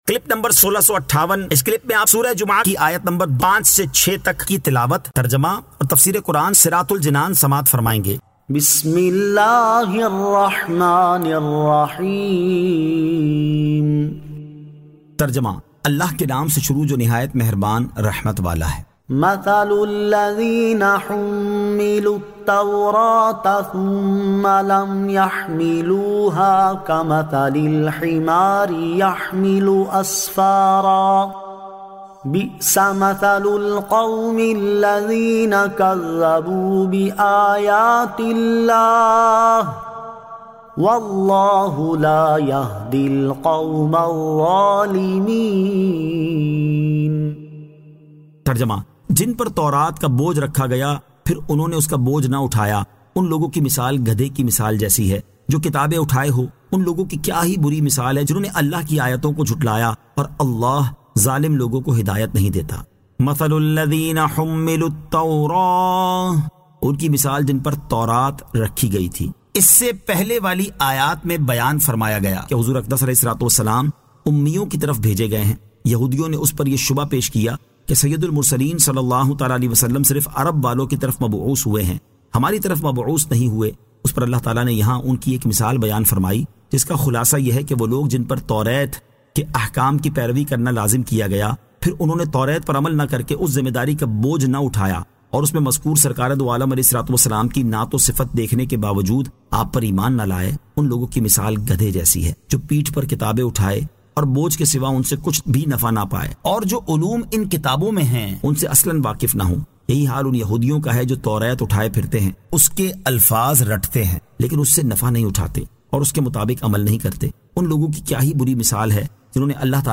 Surah Al-Jumu'ah 05 To 06 Tilawat , Tarjama , Tafseer